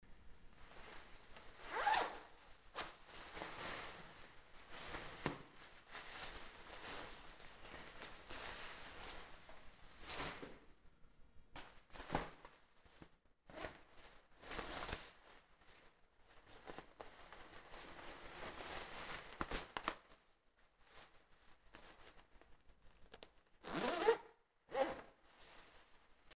26. Звук снятия и надевания зимней нейлоновой куртки, шуршание
sniatie-nadevanie-kurtki.mp3